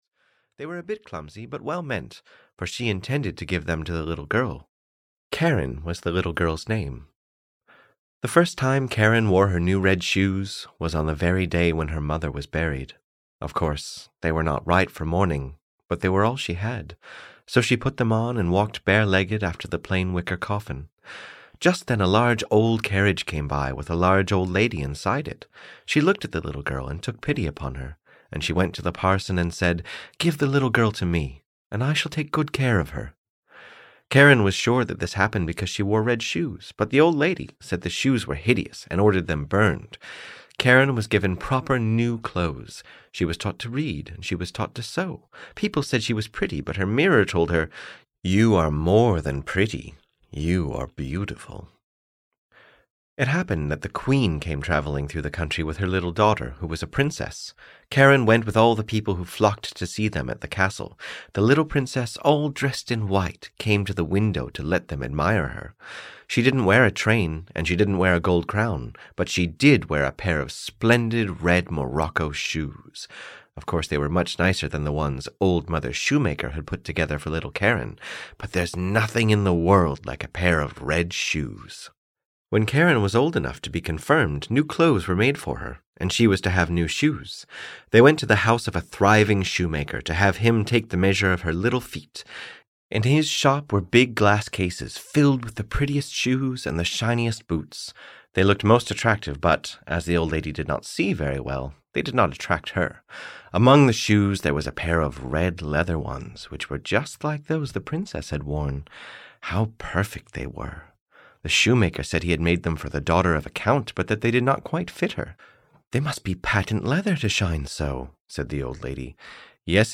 The Red Shoes (EN) audiokniha
Ukázka z knihy